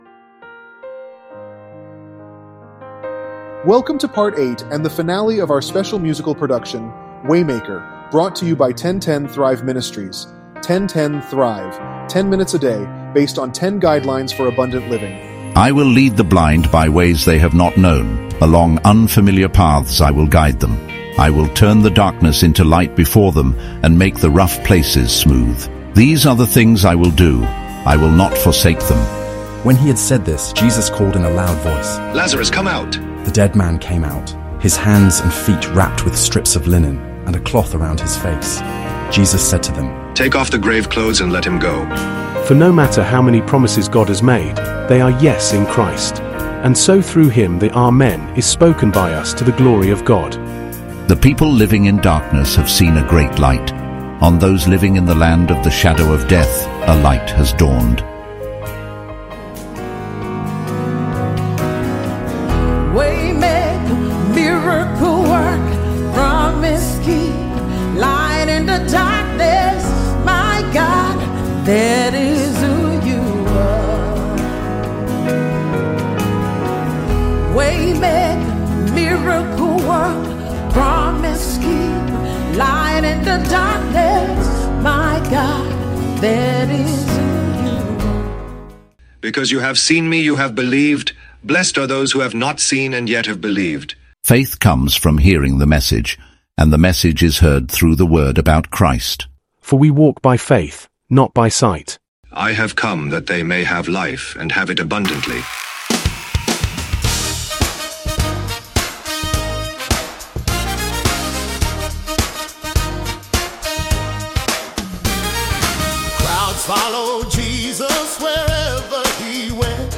It opens with the powerful miracle of Lazarus's resurrection, where Jesus, as the Waymaker, demonstrates His authority over death. This is immediately followed by a song titled "No Need for a Sign," which reinforces the message that true faith is not dependent on seeing spectacular miracles.